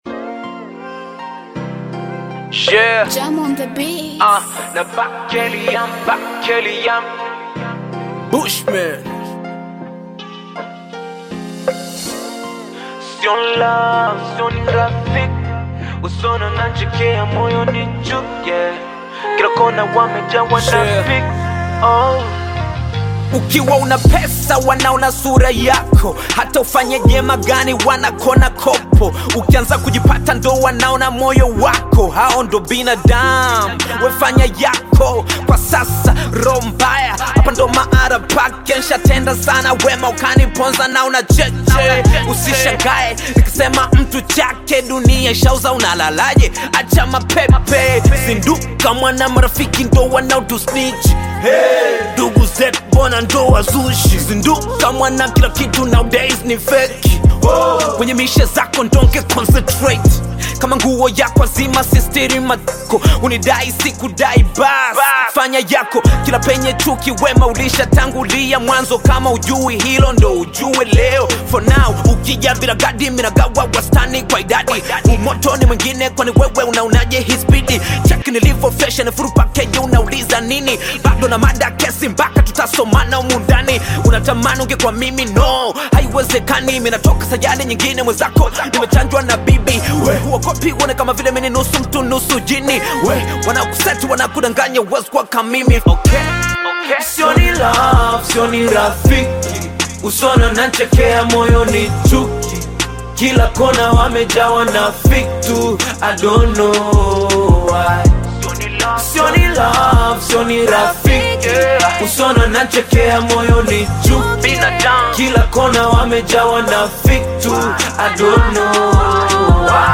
Bongo Flava music track
Tanzanian Bongo Flava artist and rapper